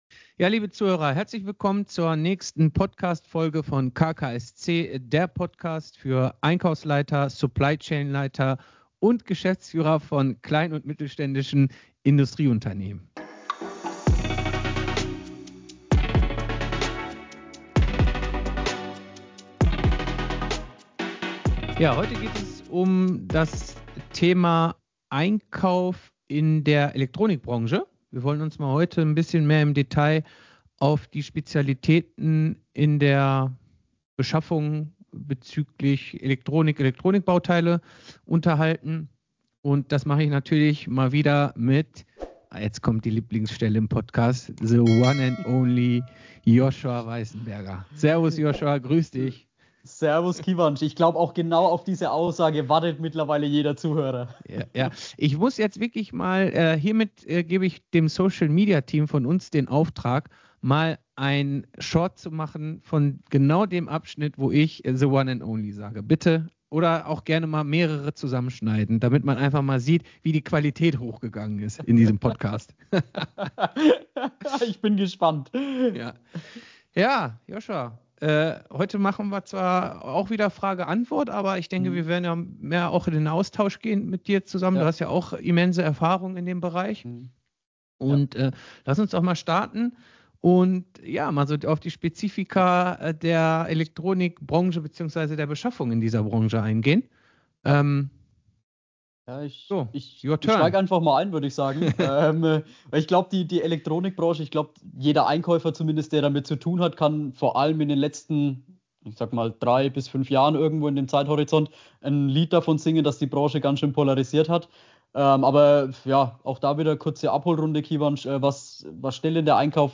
In Folge 25 unserer Podcast-Reihe sprechen wir über das Thema Einkauf in der Elektronikbranche. Zu Gast ist der Experte